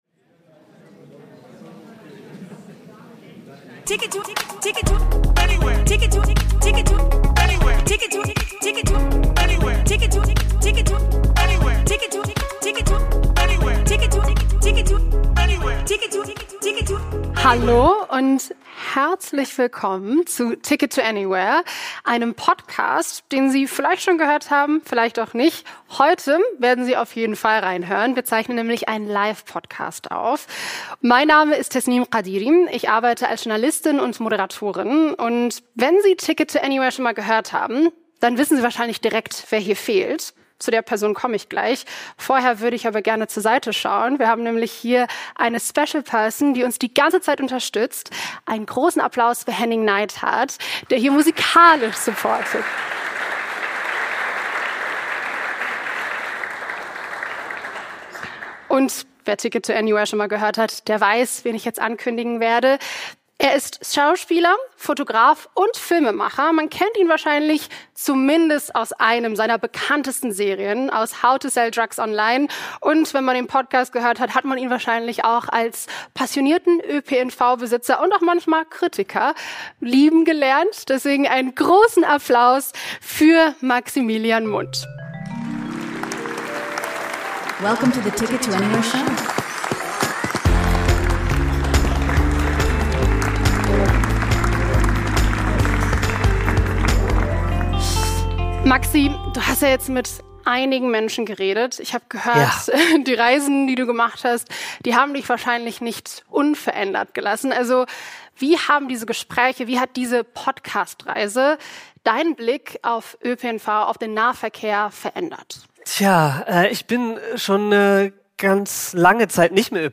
Beschreibung vor 5 Monaten Das Staffelfinale von Ticket to Anywhere feiern wir auf der IAA Mobility 2025 mit einem großen Live-Schmankerl.